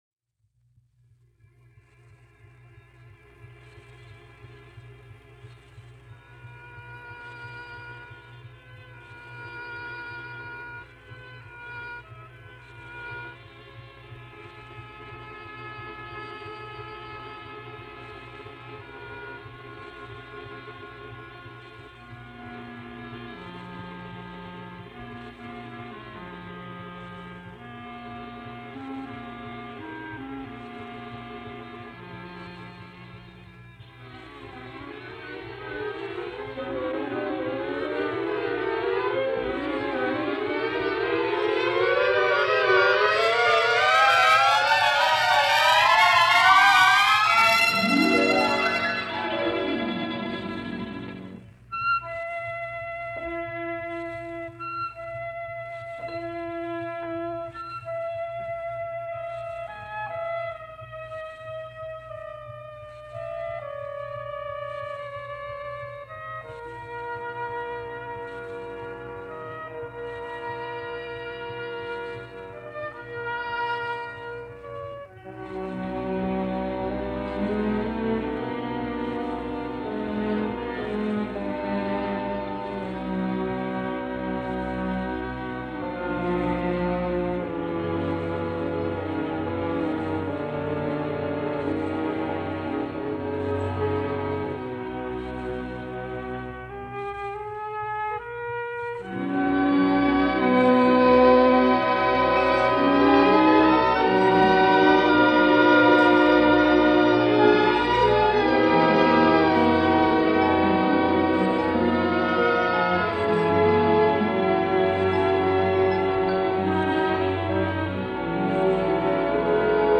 Mid-twentieth century music from Chile this week